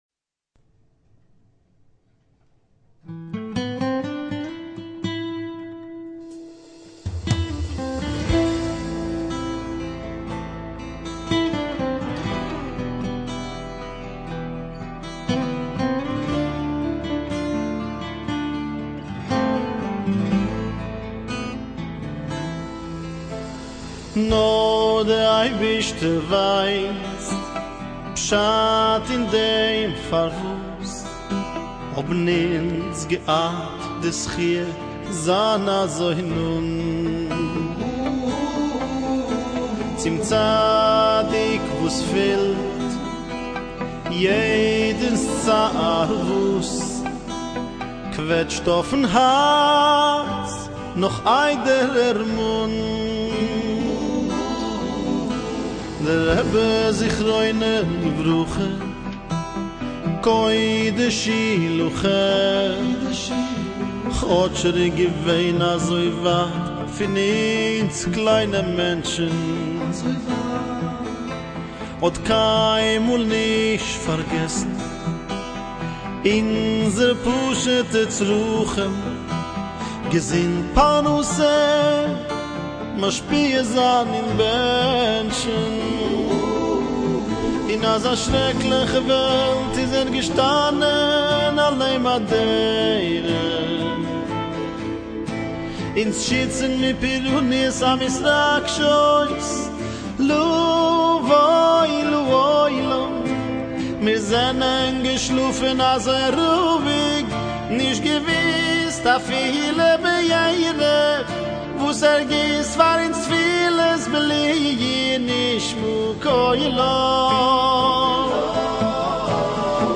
מקהלת ילדים